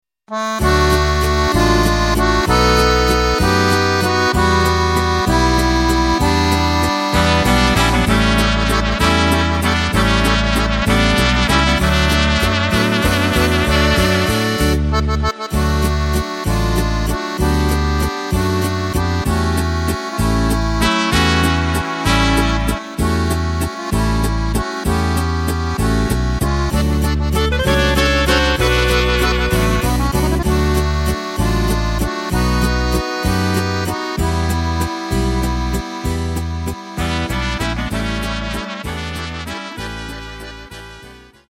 Takt:          3/4
Tempo:         193.00
Tonart:            D
Walzer aus dem Jahr 1992!